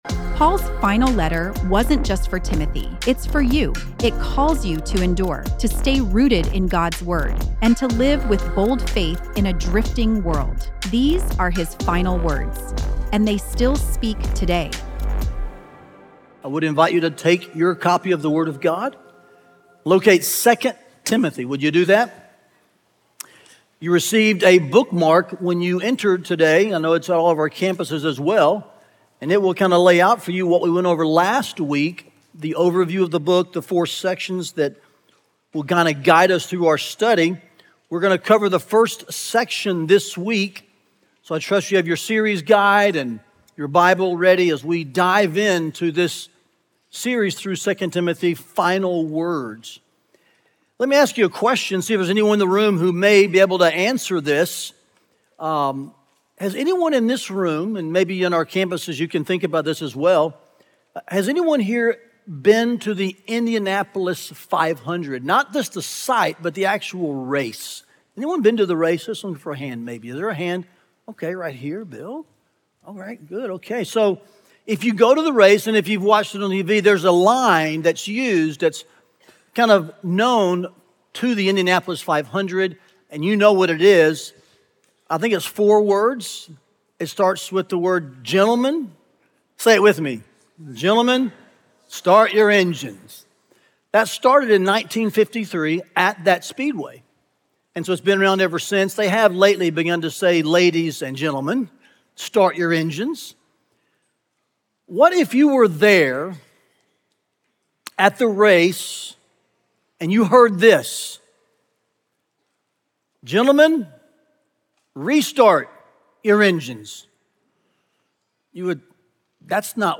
The antidote to fear is the flame of the Spirit. Listen to the latest sermon from our 2 Timothy series,